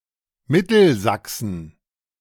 Mittelsachsen (German pronunciation: [ˈmɪtl̩ˌzaksn̩]
De-Mittelsachsen.ogg.mp3